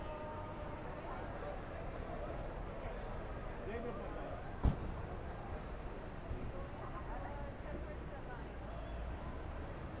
Streetsounds
Noisepollution